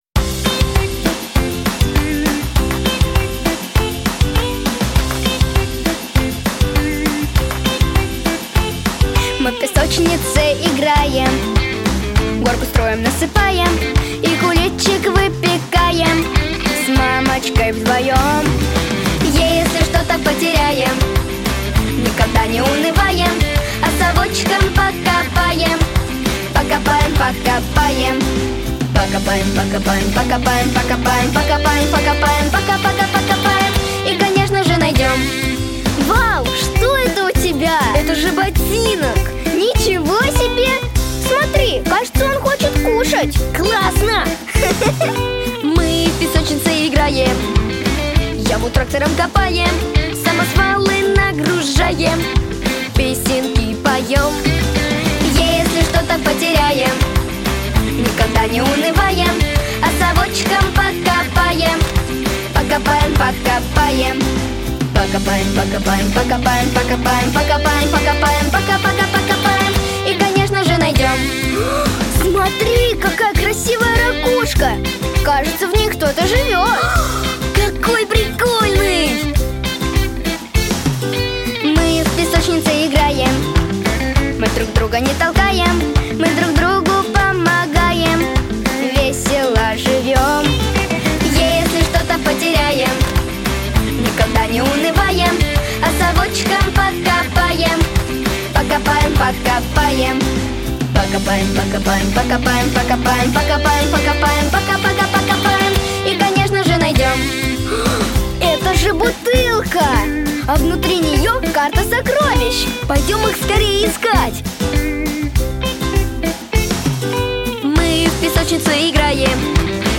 Песни из мультфильмов